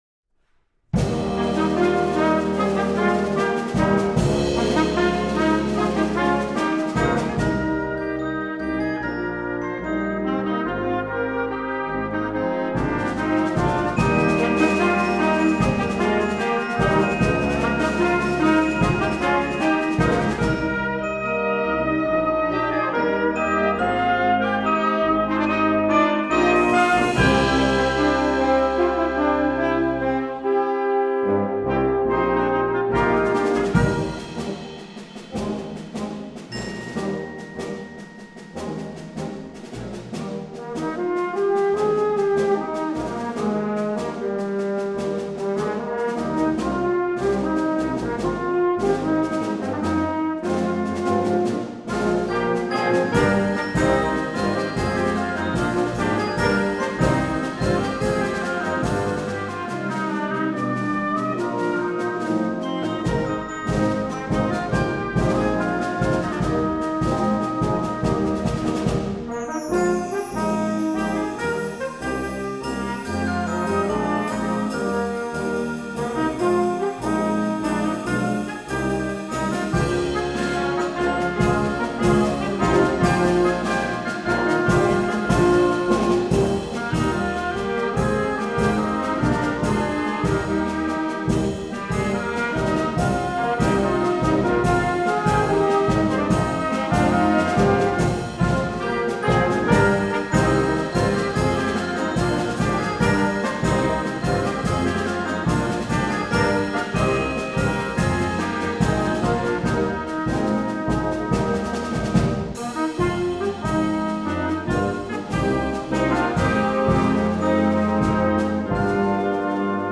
Partitions pour ensemble flexible, 7-voix + percussion.